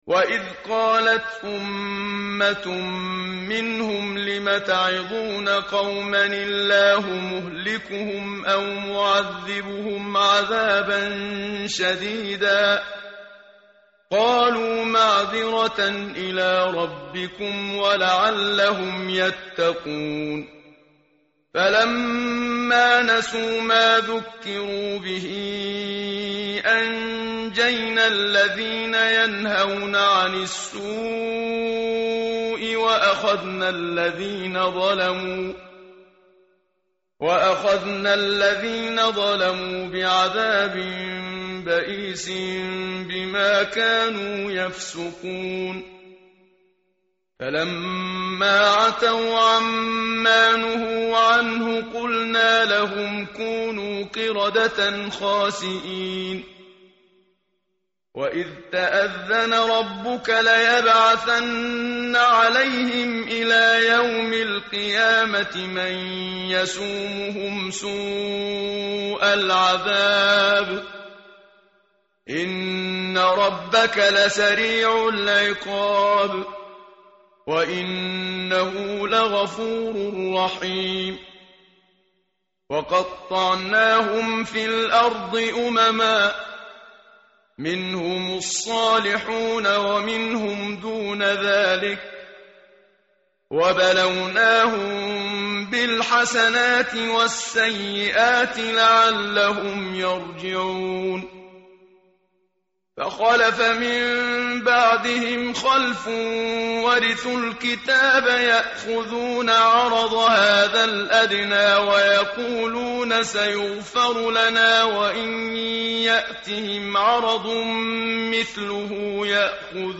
متن قرآن همراه باتلاوت قرآن و ترجمه
tartil_menshavi_page_172.mp3